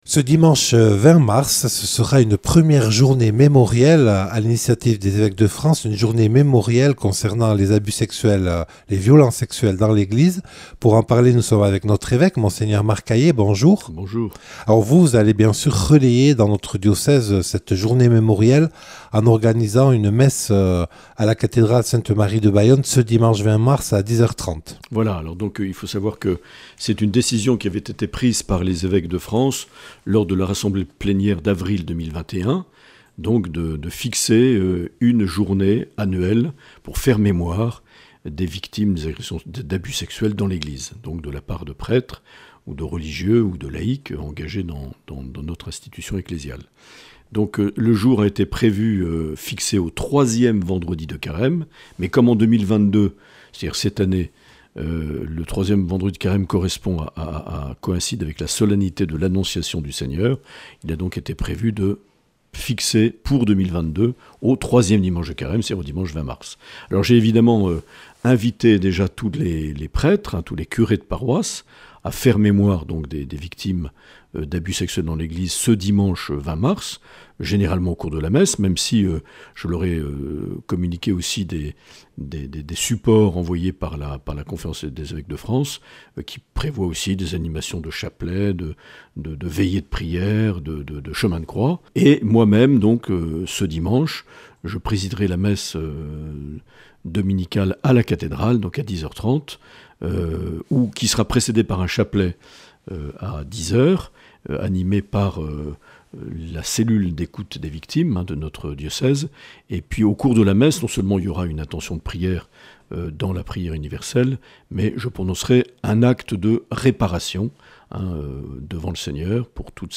Accueil \ Emissions \ Infos \ Interviews et reportages \ Journée de prière pour les victimes de violences et agressions sexuelles au (...)